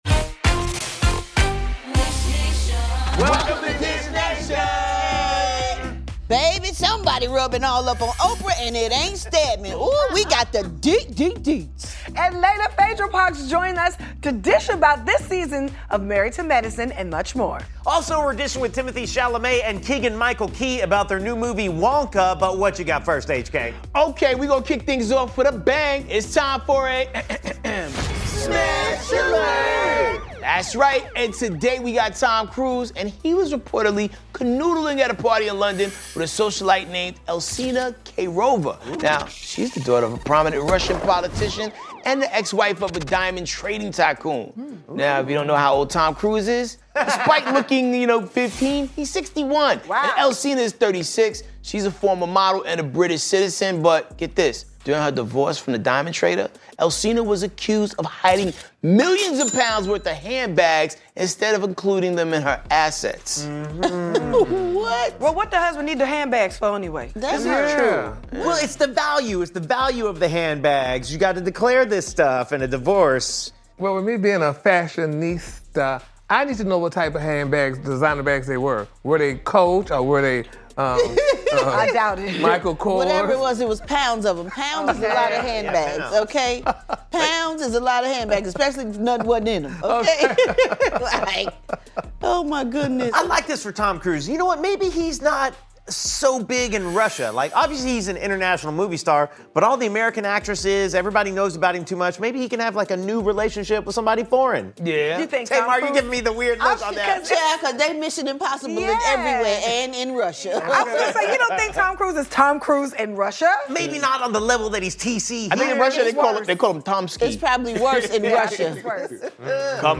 Oprah Winfrey's hands caressed by Drew Barrymore and Phaedra Parks is in studio spillin' the tea on 'Married to Medicine' Season 10!